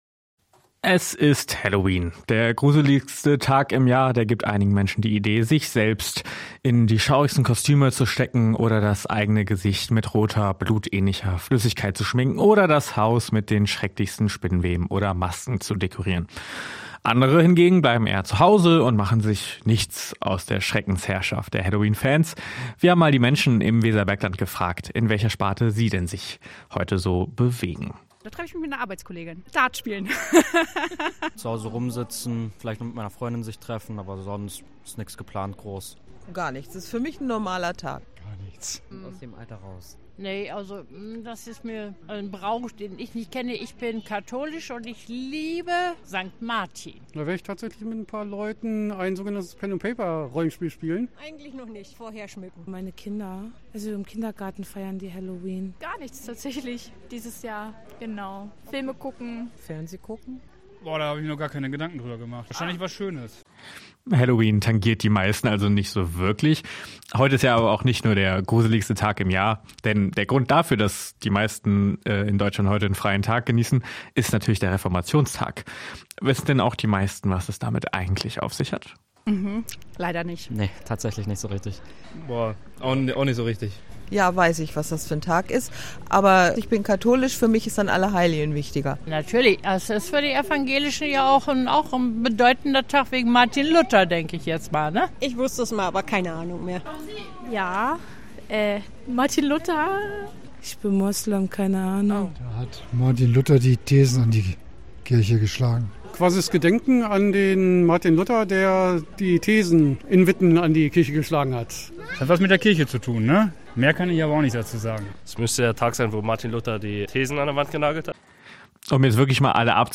Hameln-Pyrmont: Umfrage zu Halloween
hameln-pyrmont-umfrage-zu-halloween.mp3